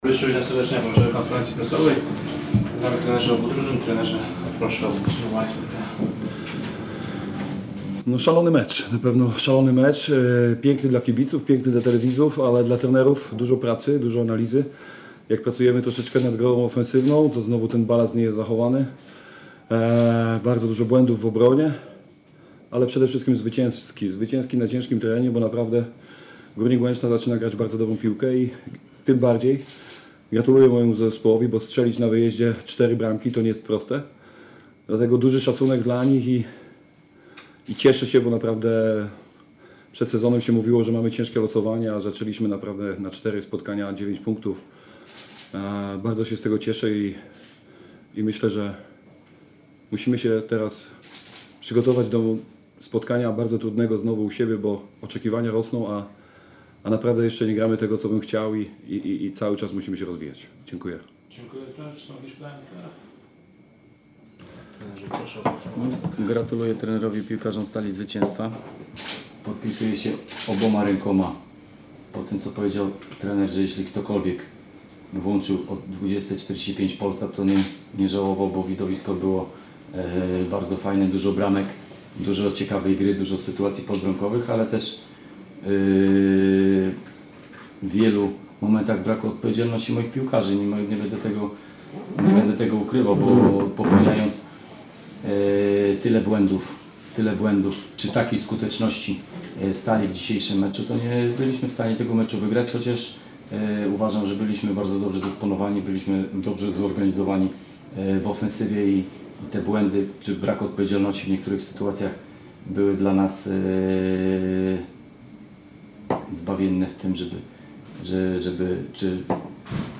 RADIO – pomeczowe komentarze trenerów: